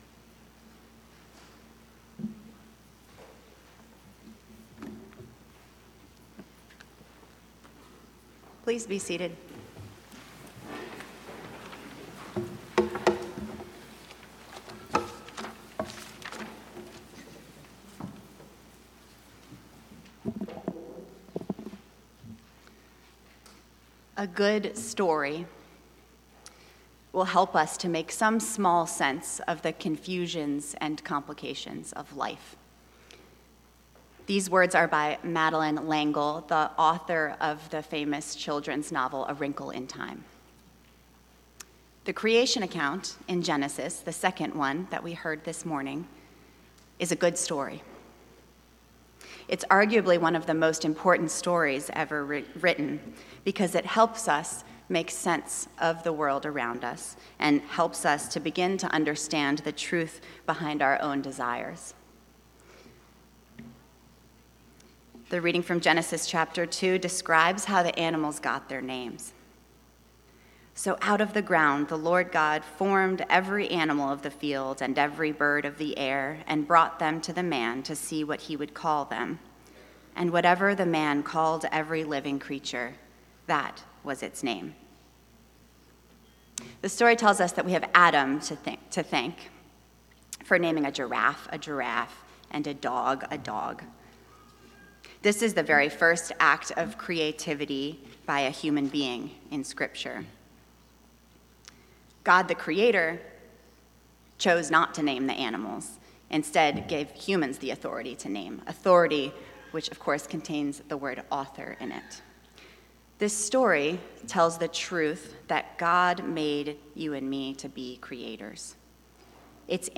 St-Pauls-HEII-9a-Homily-06OCT24.mp3